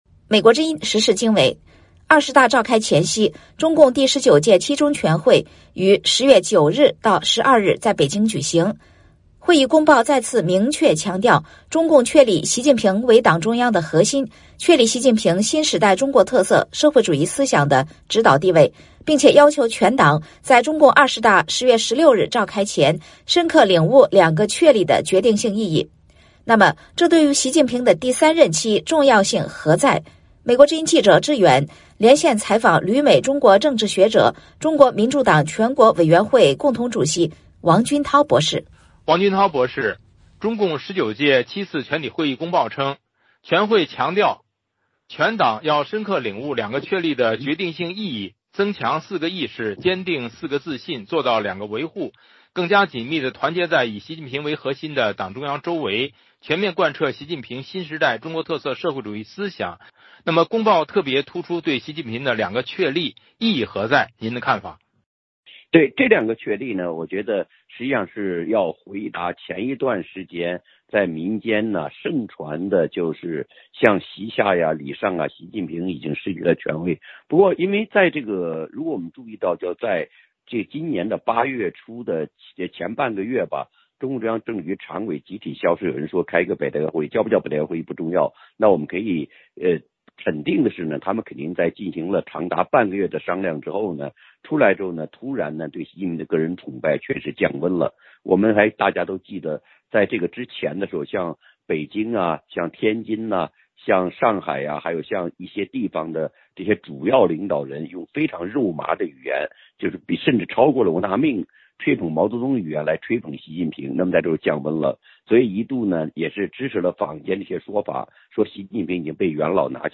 VOA连线：中国共产党第十九届中央委员会第七次全体会议，于2022年10月9日至12日在北京举行。会议公报再次明确强调：中共确立习近平为党中央的核心、全党的核心地位，确立习近平新时代中国特色社会主义思想的指导地位。